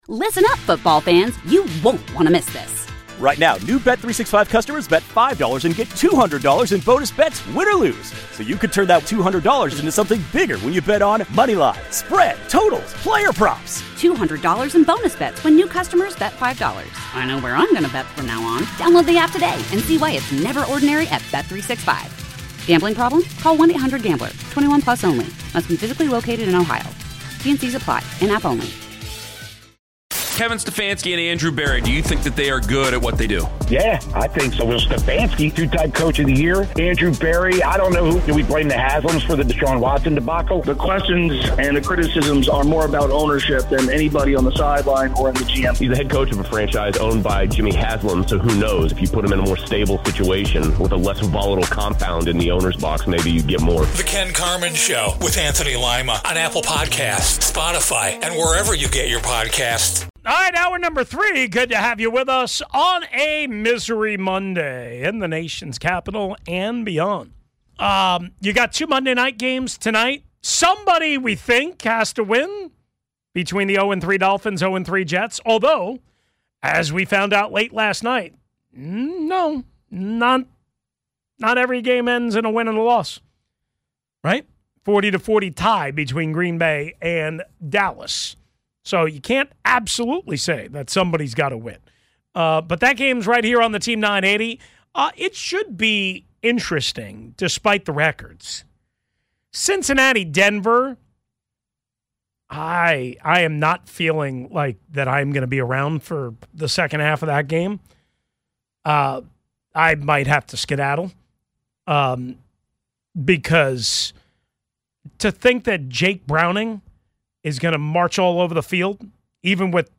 Then, Dan Quinn speaks to the media about player availability and the improvements the team needs to make. To wrap it up, we break down the holes in the Commanders’ zone defense and discuss why the team is still building.